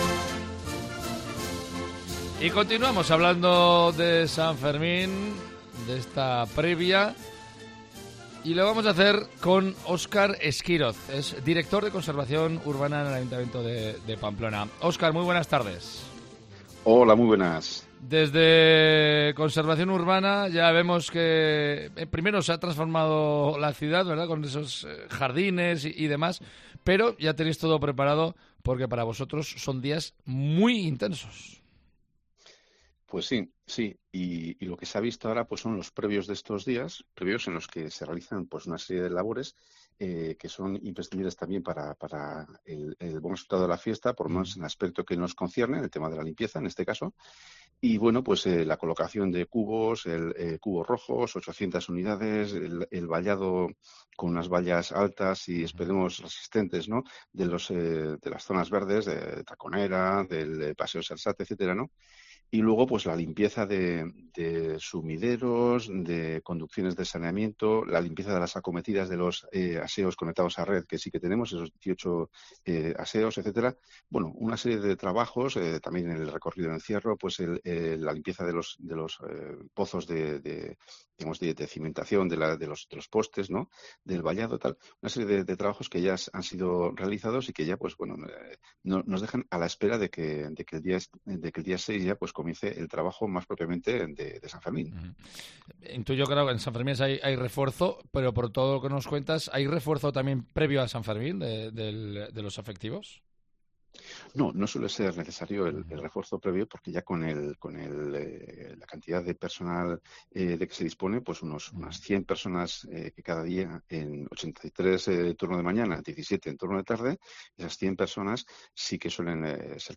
lo cuenta en los micrófonos de Cope Navarra.